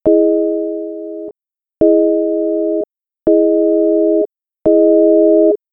EXAMPLE: SL slider being raised from minimum (lowest sustain volume) to maximum, with DR set to minimum: